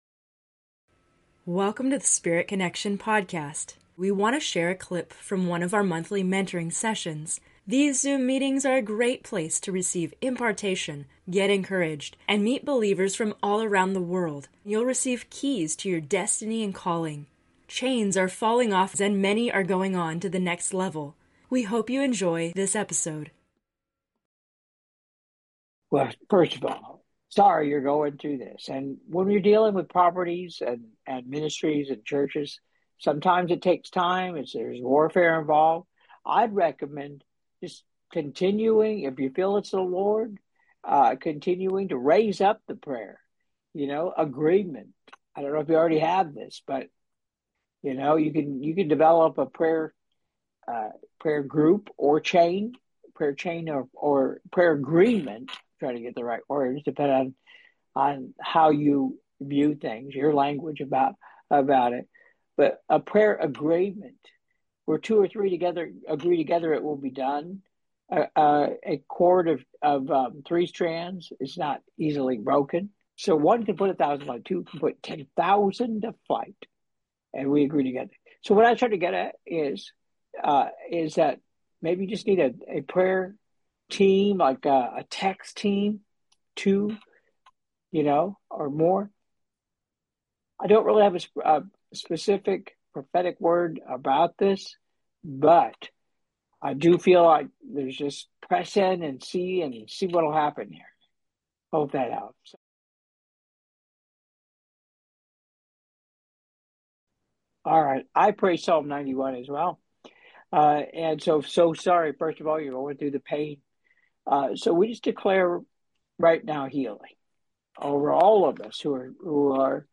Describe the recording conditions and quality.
In this episode of Spirit Connection, we have a special excerpt from a Q&A session in a recent Monthly Mentoring Session.